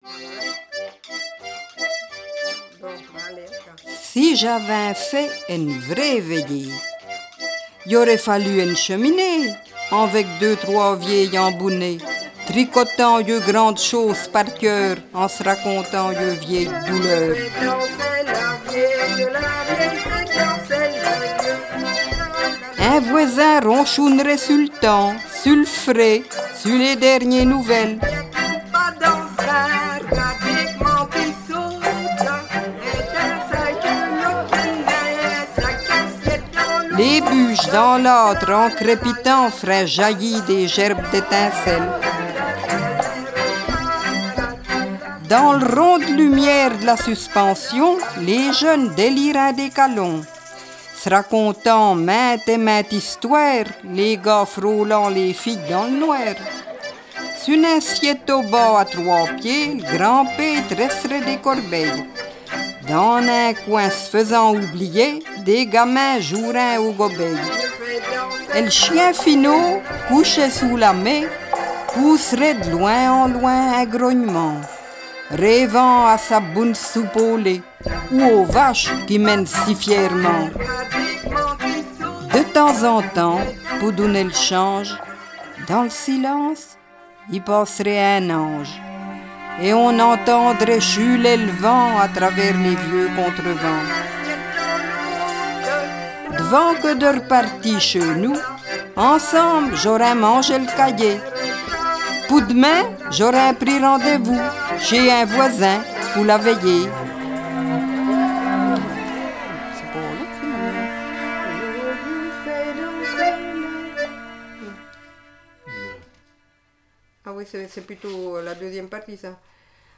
Contes patoisants